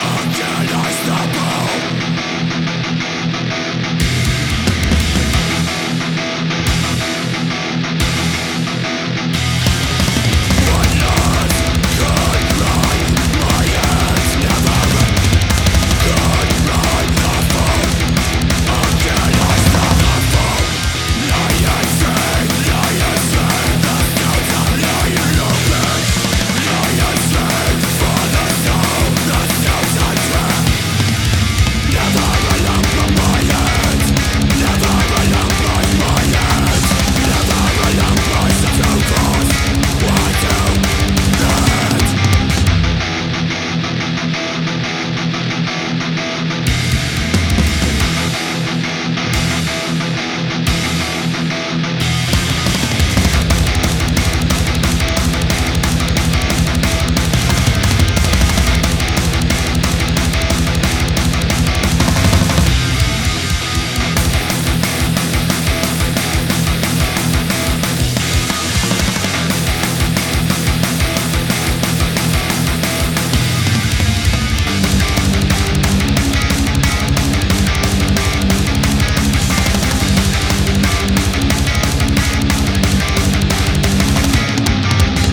デスメタル
ホラーBGM